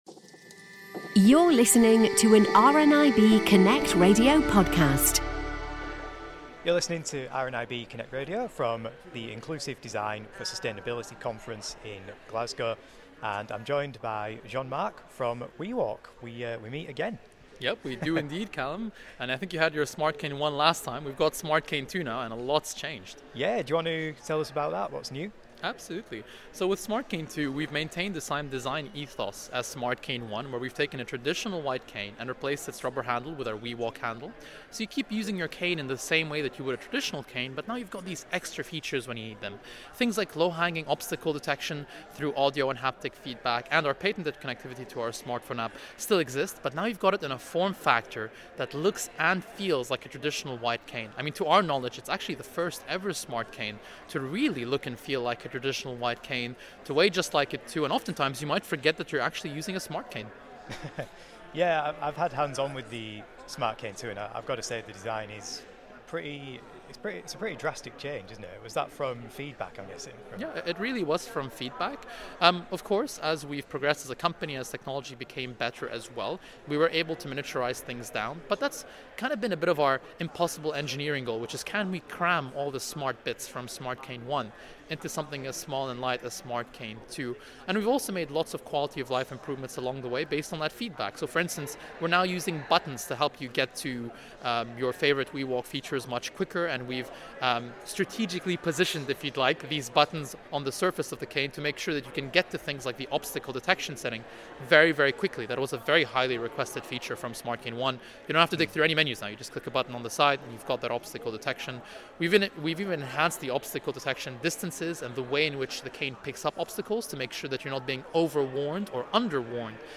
More from the big tech and accessibility conference in Glasgow